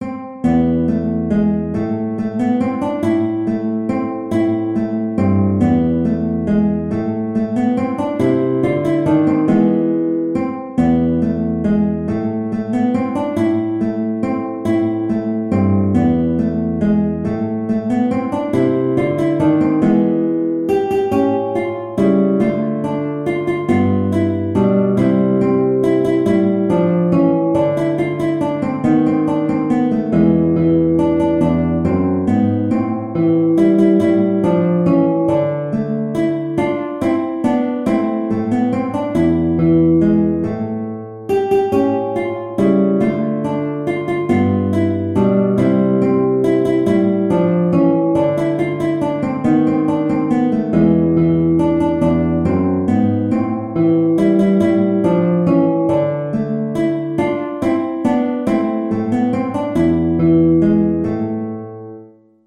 Guitar version
3/4 (View more 3/4 Music)
E3-G5
Guitar  (View more Easy Guitar Music)
Traditional (View more Traditional Guitar Music)